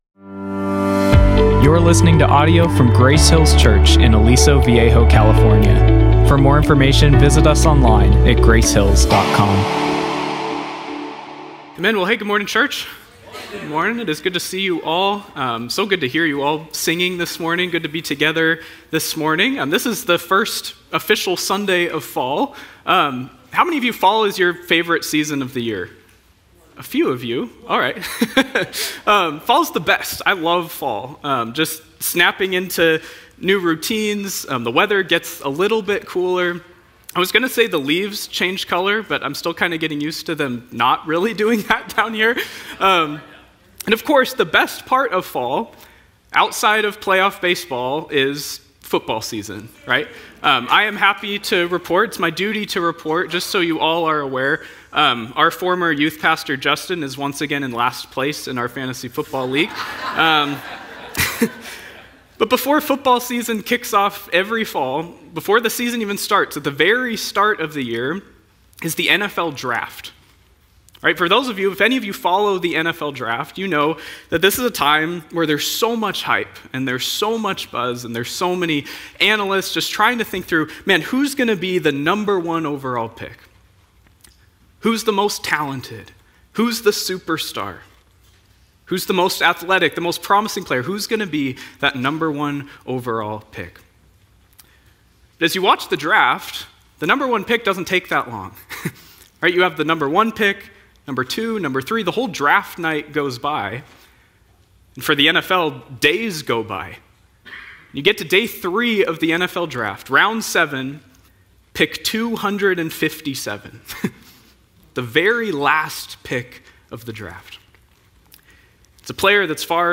Listen online to this week's message or search our archive of messages by series, speaker or topic.